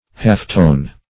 Half-tone \Half"-tone`\ (h[aum]f"t[=o]n`), a.
half-tone.mp3